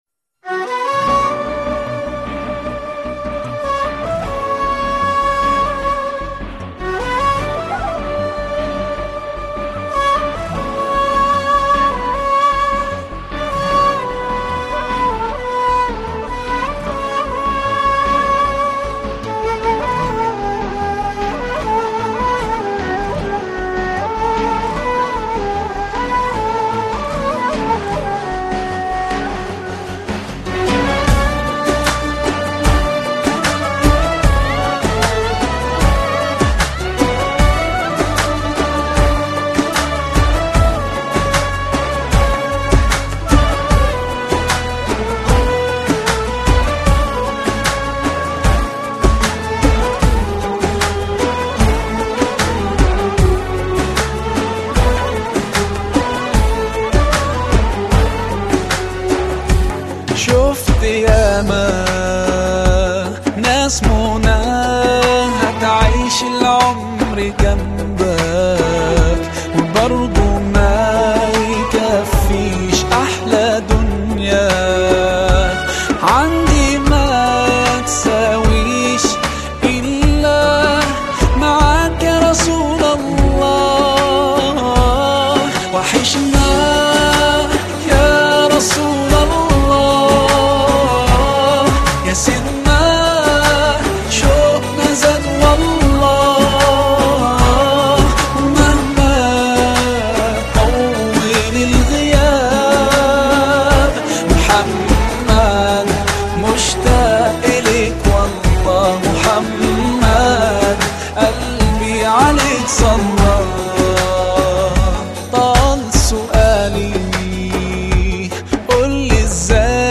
Arabic Islamic Song